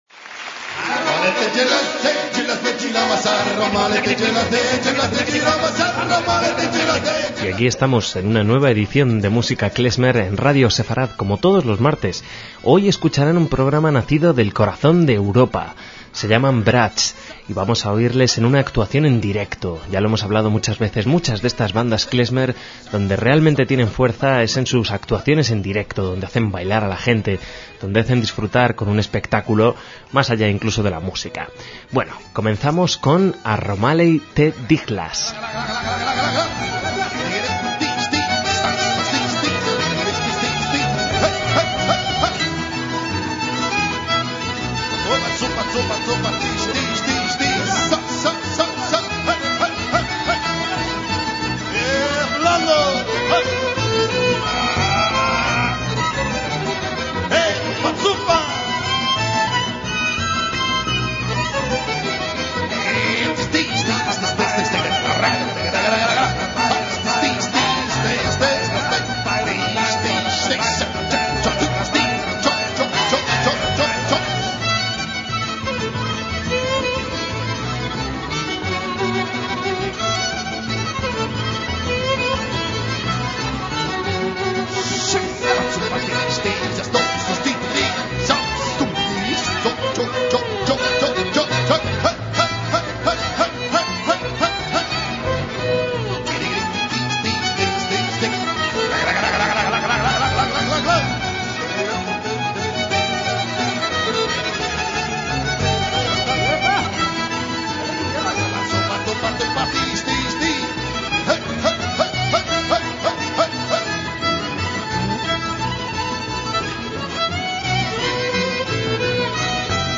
MÚSICA KLEZMER
influencias de las músicas gitana, klezmer y jazz